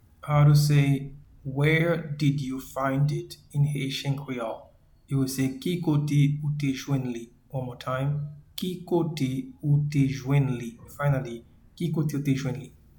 Pronunciation and Transcript:
Where-did-you-find-it-in-Haitian-Creole-Ki-kote-ou-te-jwenn-li.mp3